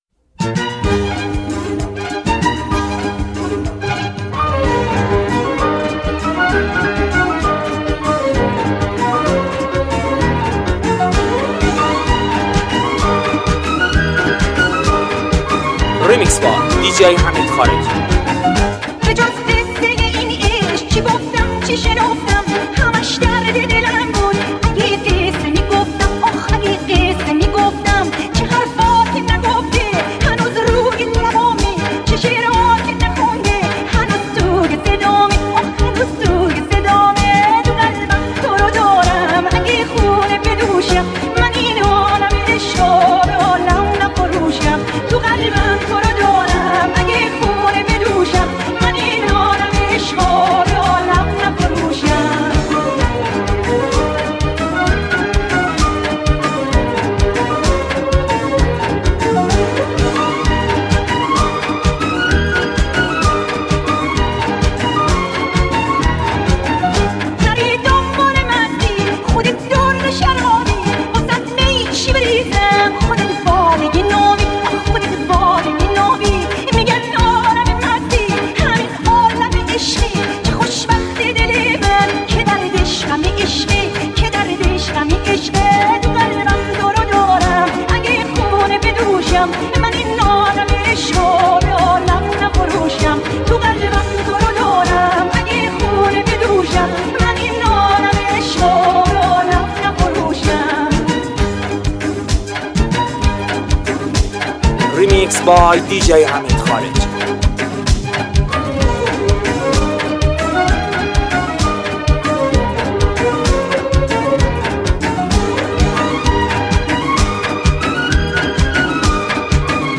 یه ریمیکس نوستالژیک و جذاب